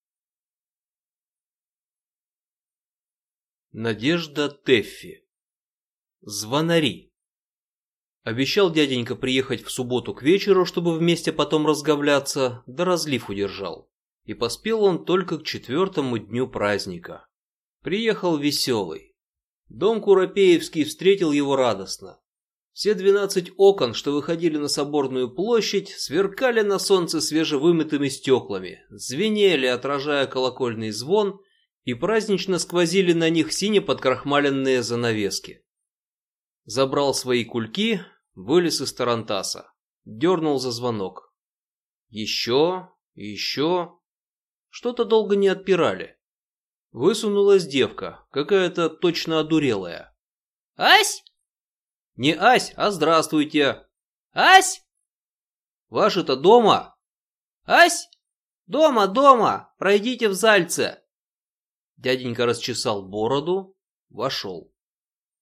Аудиокнига Звонари | Библиотека аудиокниг
Прослушать и бесплатно скачать фрагмент аудиокниги